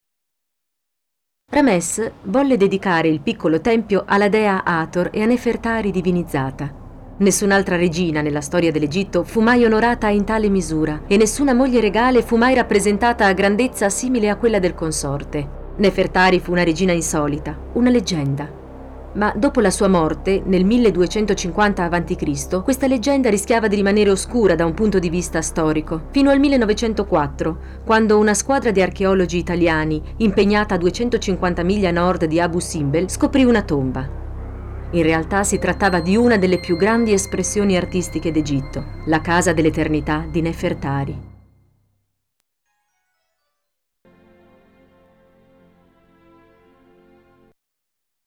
Sprecherin italienisch.
Sprechprobe: Sonstiges (Muttersprache):
female italian voice over talent.